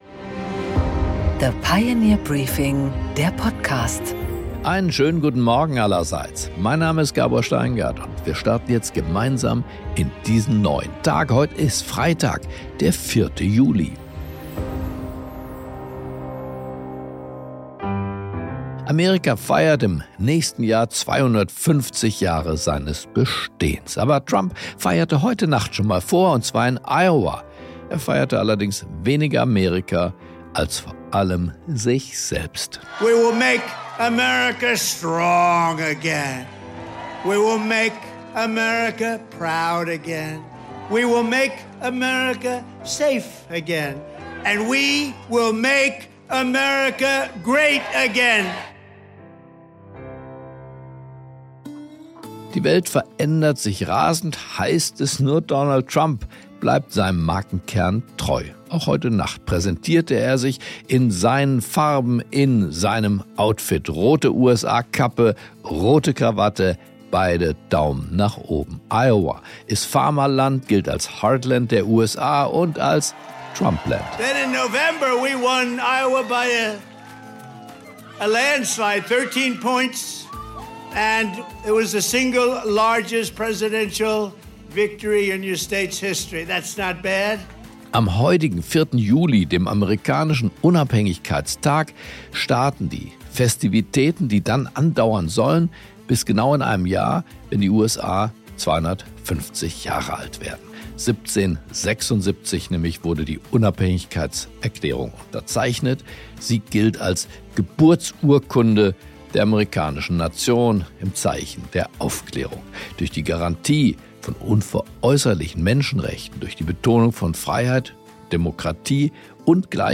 Gabor Steingart präsentiert das Pioneer Briefing
Im Interview: Carsten Linnemann, MdB und CDU-Generalsekretär, spricht mit Gabor Steingart über seine Sicht auf die schwarz-rote Regierung.